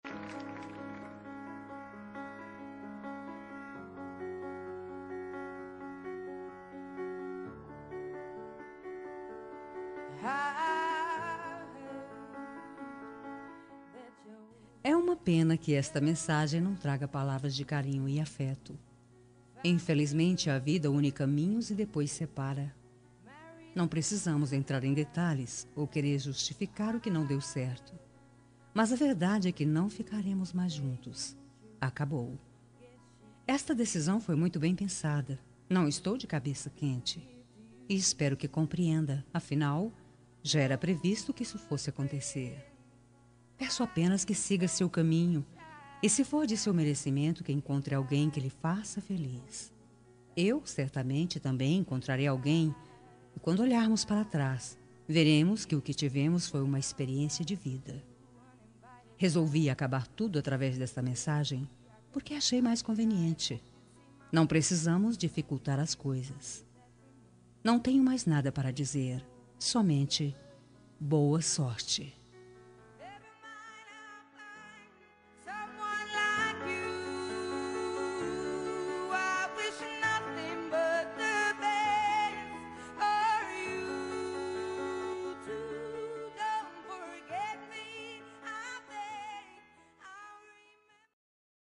Término – Voz Feminina – Cód: 8660